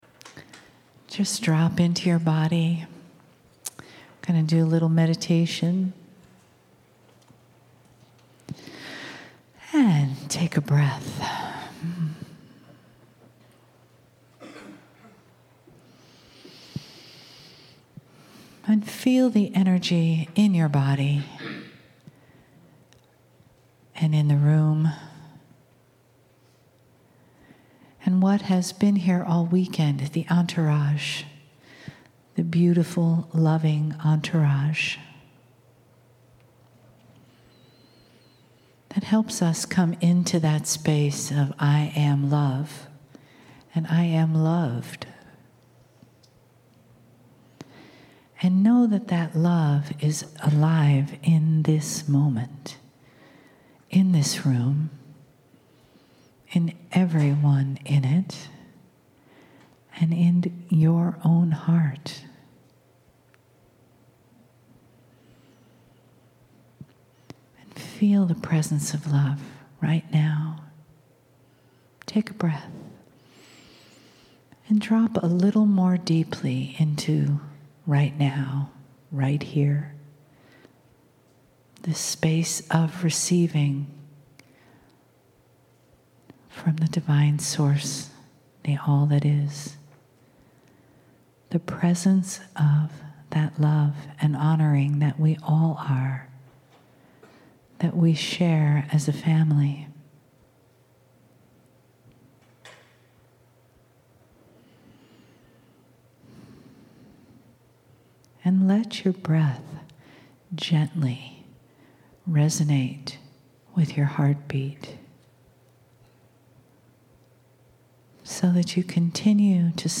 San Antonio, Texas Saturday & Sunday - January 23-24, 2016
KRYON CHANNELLING